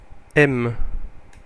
Ääntäminen
Ääntäminen France (Paris): IPA: [ɛm] Tuntematon aksentti: IPA: /m/ Haettu sana löytyi näillä lähdekielillä: ranska Käännös Konteksti Erisnimet 1.